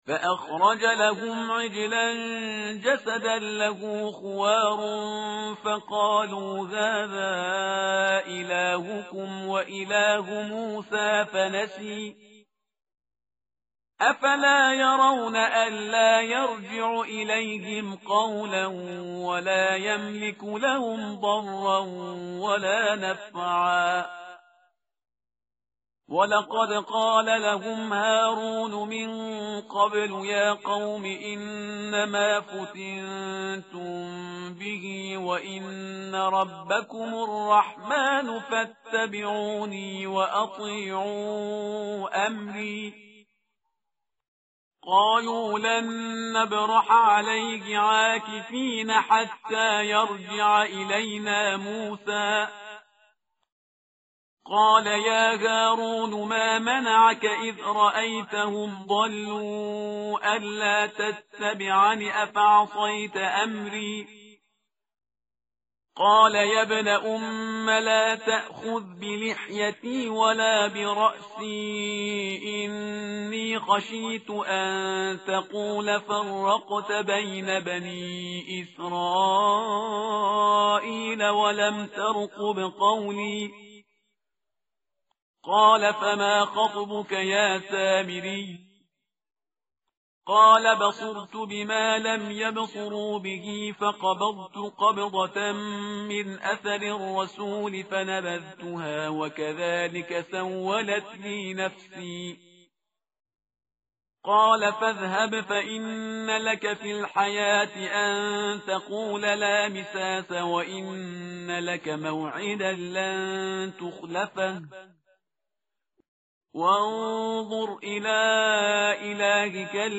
متن قرآن همراه باتلاوت قرآن و ترجمه
tartil_parhizgar_page_318.mp3